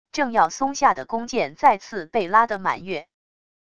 正要松下的弓箭再次被拉的满月wav音频